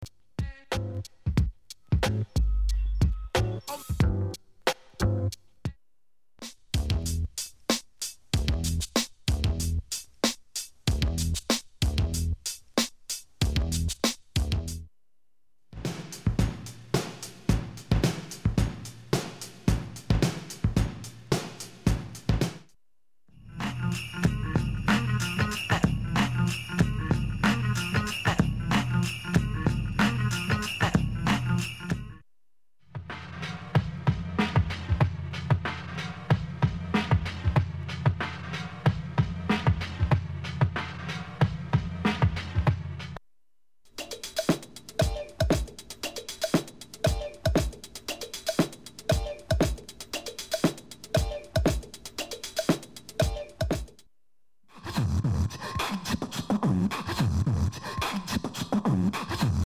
HIP HOP/R&B
定番ブレイクを集めたDJ TOOL!!